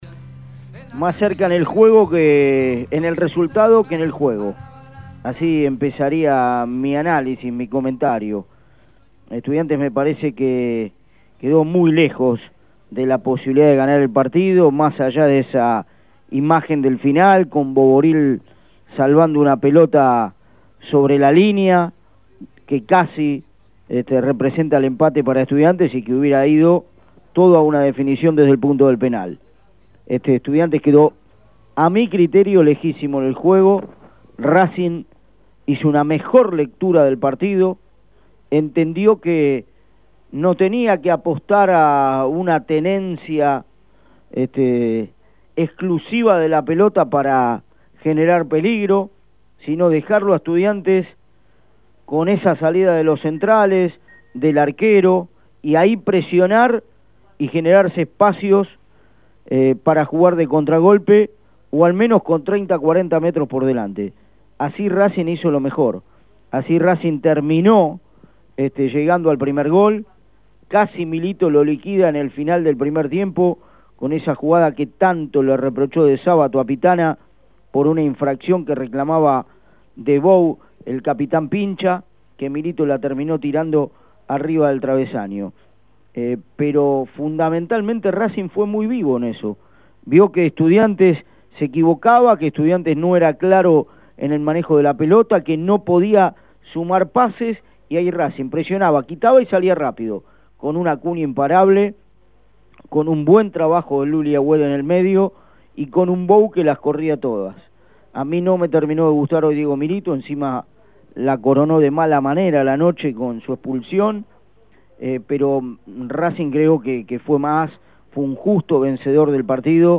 comentario-final.mp3